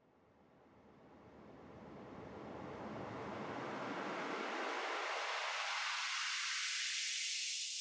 DBDKII - Skyfall Riser.wav